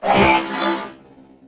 CosmicRageSounds / ogg / general / combat / enemy / droid / hurt3.ogg
hurt3.ogg